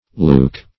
Leuc- \Leuc-\ (l[=u]k-) pref.